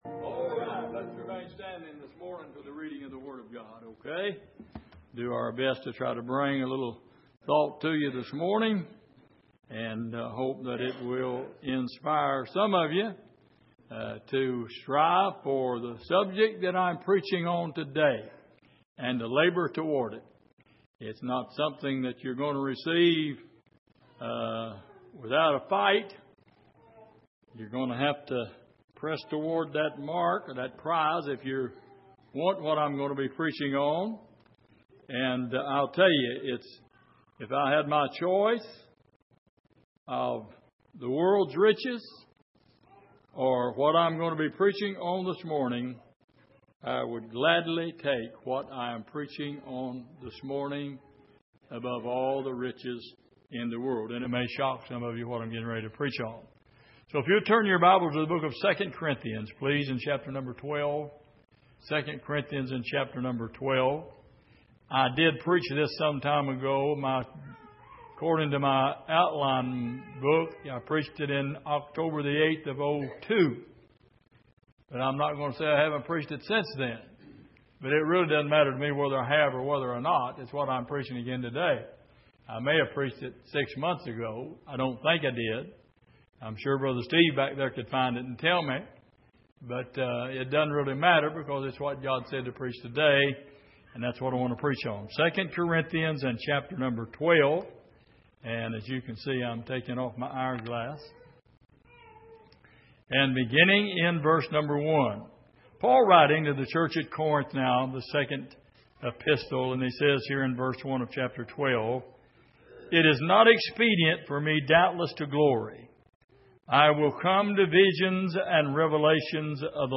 Passage: 2 Corinthians 12:1-10 Service: Sunday Morning